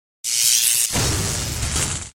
PetSkill_Thunderbolt.MP3